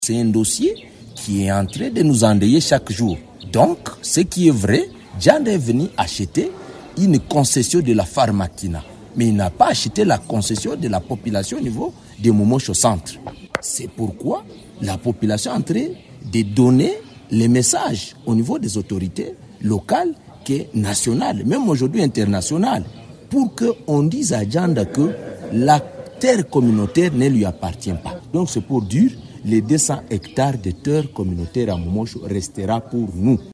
Ils l’ont signifié à l’occasion d’une émission publique organisée à Mumosho par Radio Maendeleo Dimanche 8 décembre 2024.